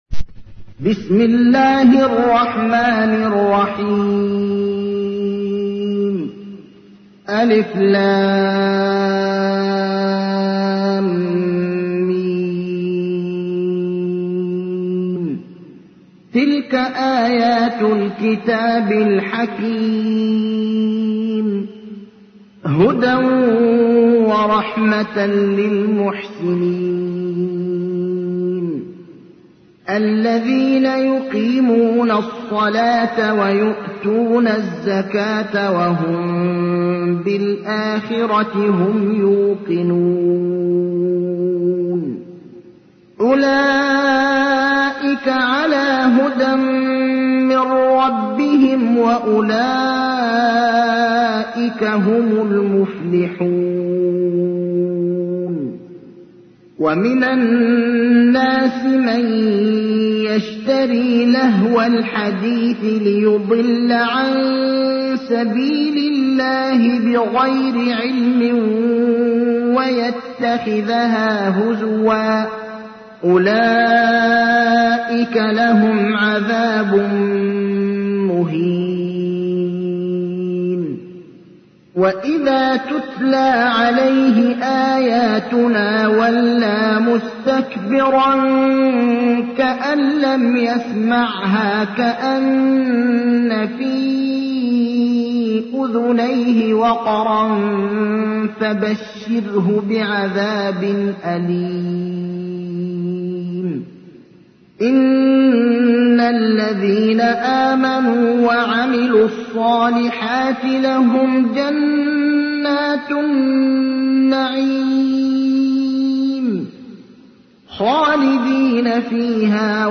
تحميل : 31. سورة لقمان / القارئ ابراهيم الأخضر / القرآن الكريم / موقع يا حسين